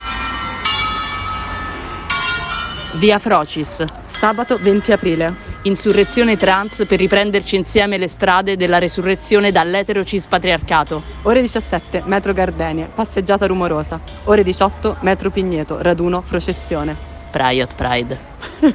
Spot via frocis